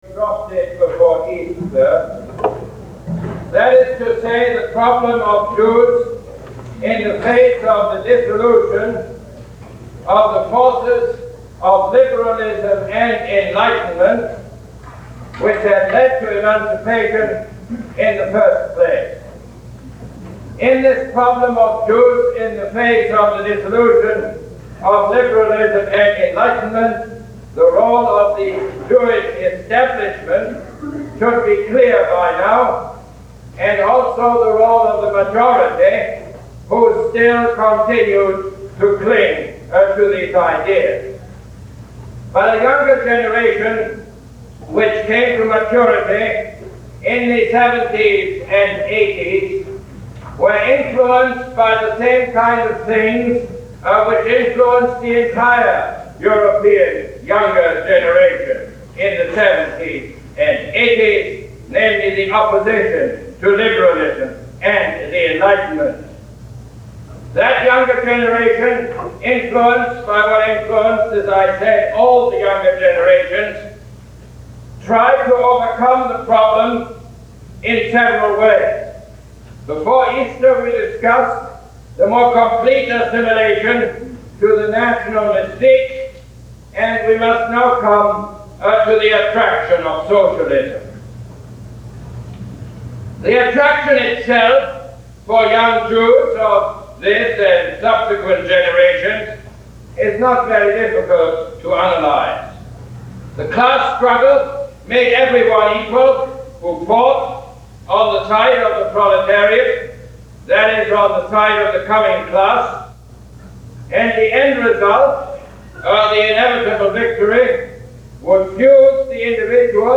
Lecture #21 - April 18, 1971